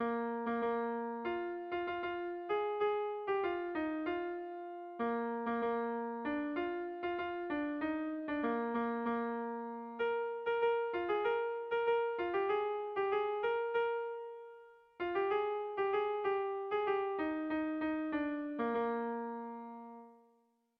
Erromantzea
Bertsoa 4ko txiki izan arren, doinua 8ko txikitxat hartu dut.
Zortziko txikia (hg) / Lau puntuko txikia (ip)
A1A2BD